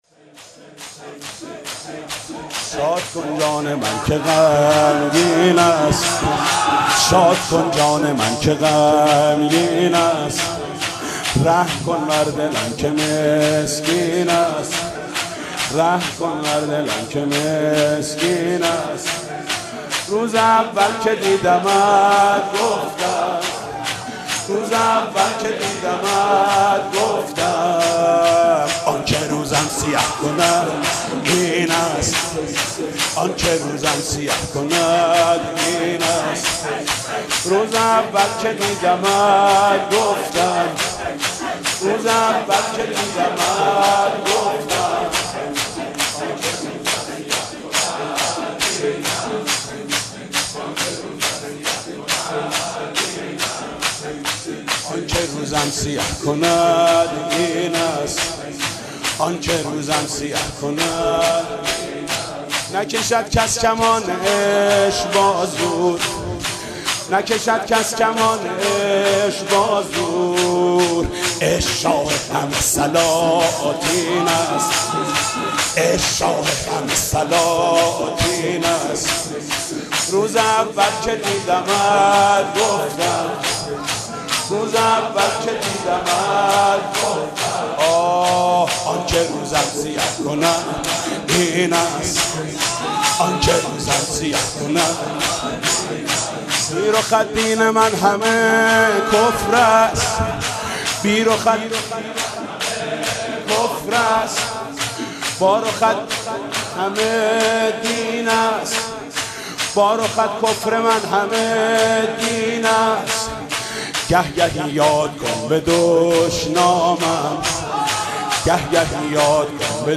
مداح
مناسبت : وفات حضرت ام‌البنین سلام‌الله‌علیها
مداح : محمود کریمی قالب : شور